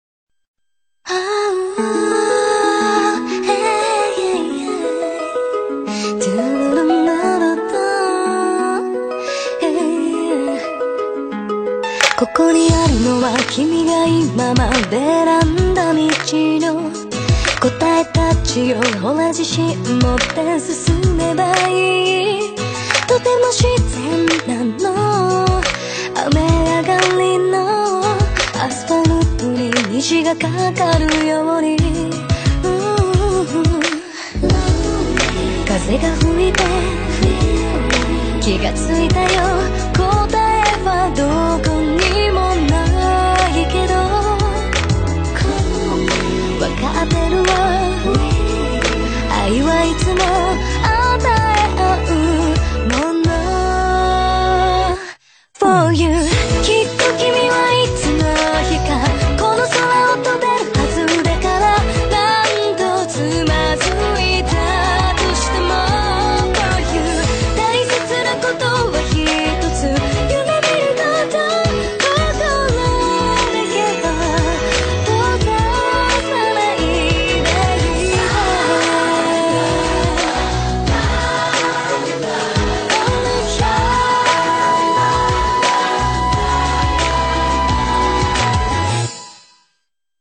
BPM88-88
Audio QualityMusic Cut